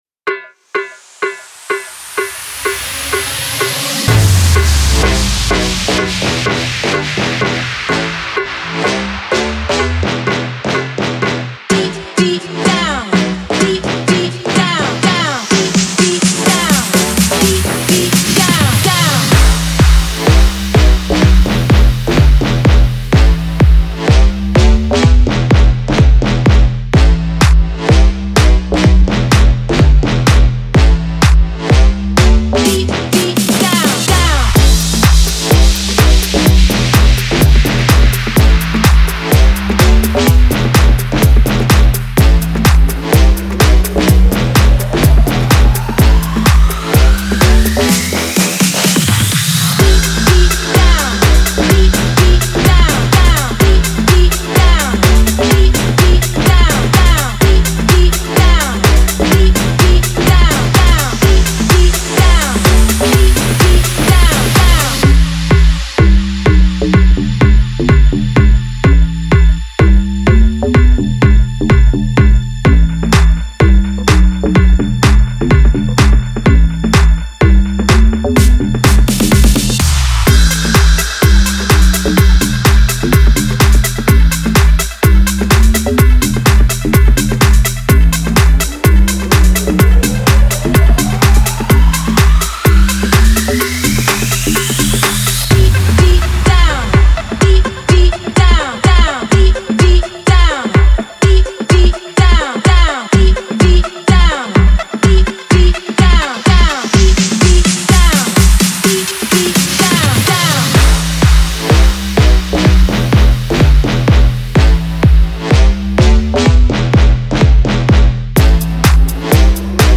اهنگ انگیزشی برای باشگاه